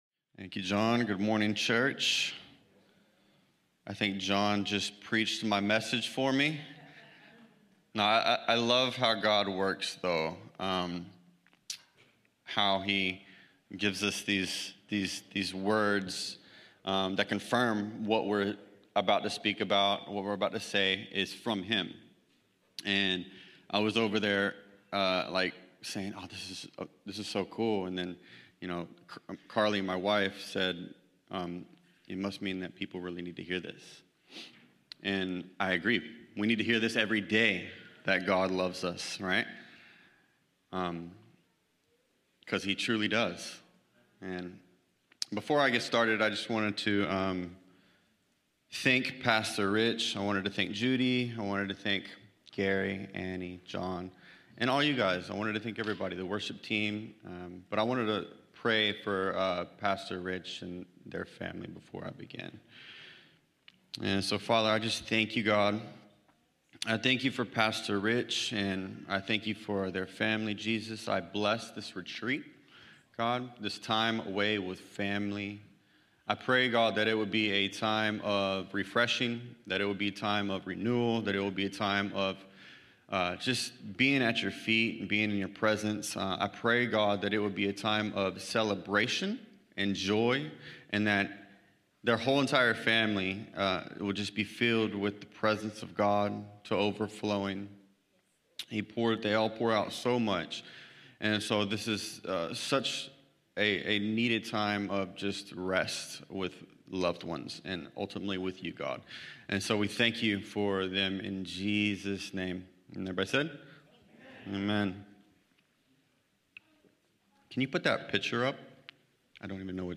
1 John Watch Listen Save Cornerstone Fellowship Sunday morning service, livestreamed from Wormleysburg, PA.